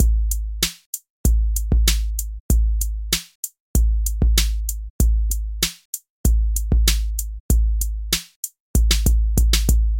描述：电环96
Tag: 96 bpm Electronic Loops Drum Loops 1.68 MB wav Key : Unknown